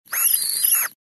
Звук пищащего паука